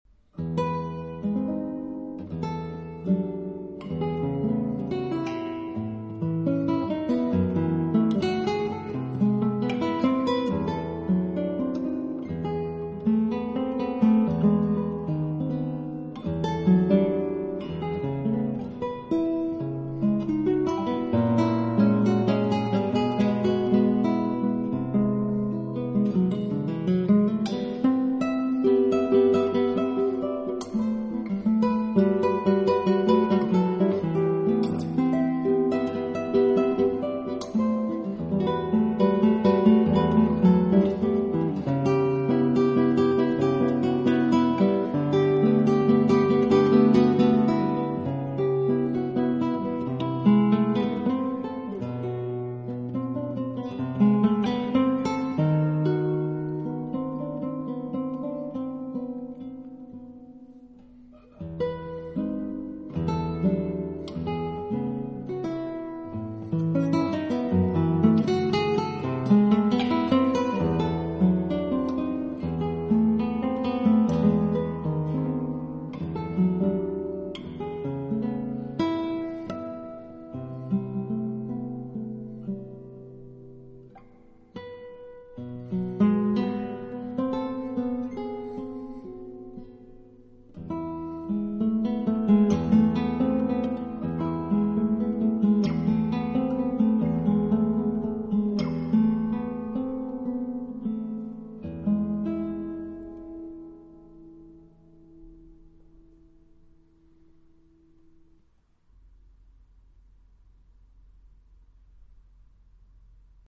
【爵士吉他】
一张木吉他的独奏，不知道会让多少人联想到乏味与沉闷，也不知道会证明多少人的乏味
没有语言，没有其他，一把木吉他，是久违的音乐最原始的根基：交流。
出的是从容、平和与宽容。